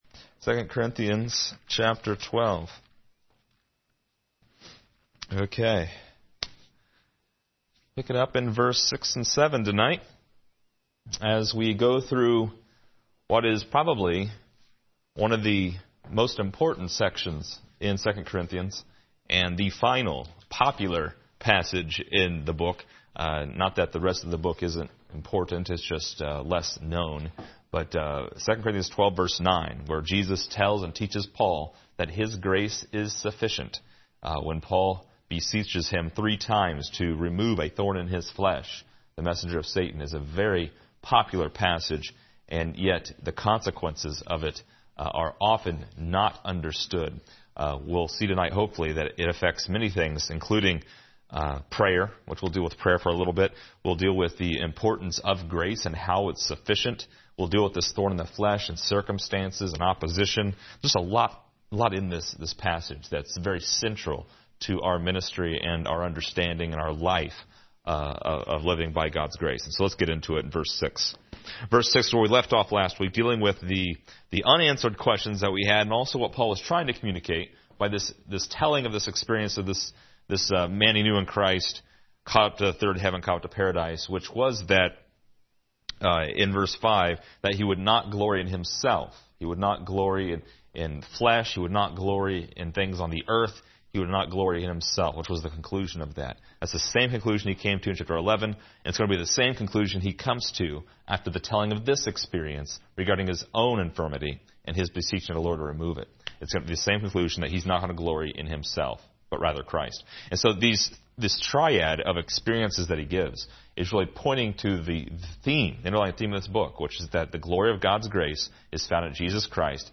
Description: This lesson is part 50 in a verse by verse study through 2 Corinthians titled: Sufficient Grace.